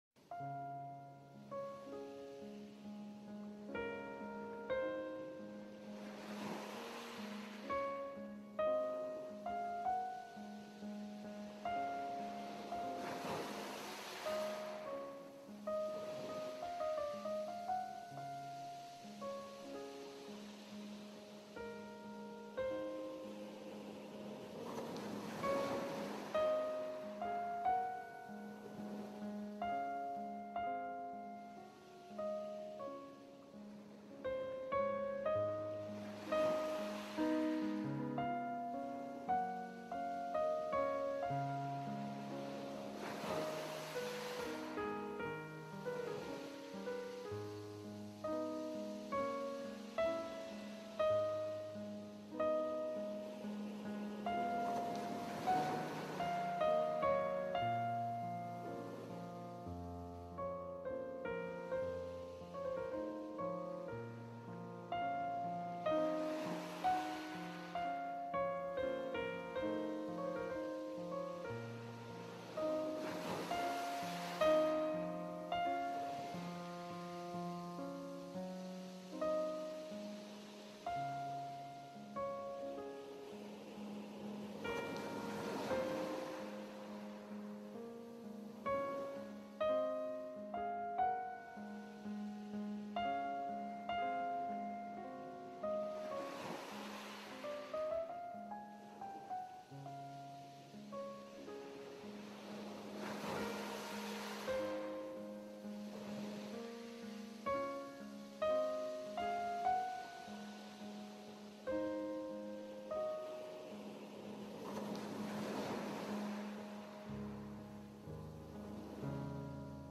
Weihnachts-Kaffeehaus-Ambiente | Pomodoro 50-10
Es beginnt leise.